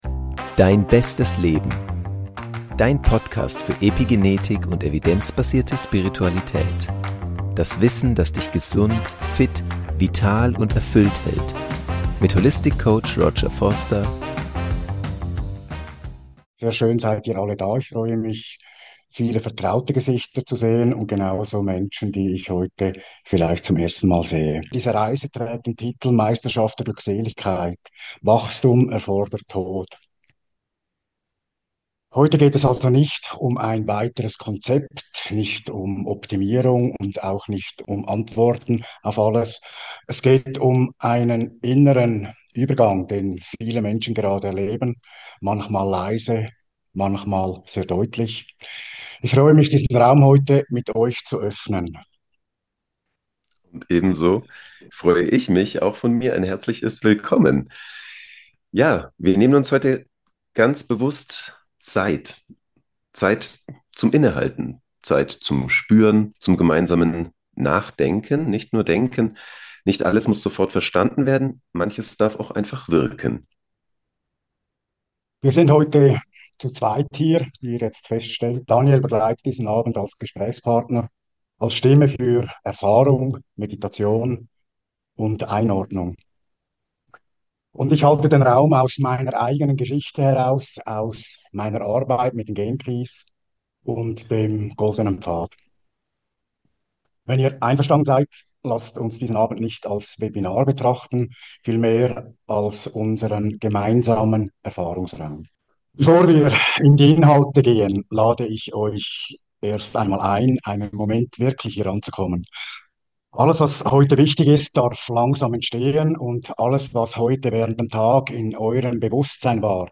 Das Video ist die Aufzeichnung des Webinars "Wachstum erfordert Tod" vom 28.01.2026.